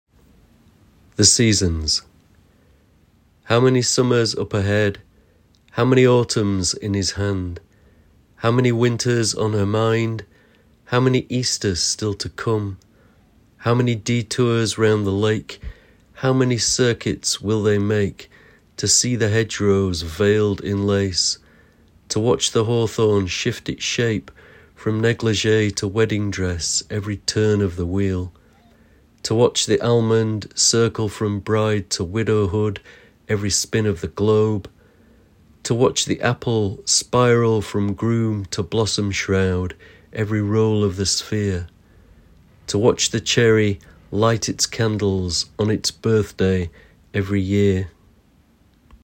Poet Laureate Simon Armitage reads his poem The Seasons. The Seasons is part of Blossomise, a collection of poetry and music launched in 2024 to celebrate the arrival of spring.